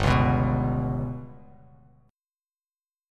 GMb5 chord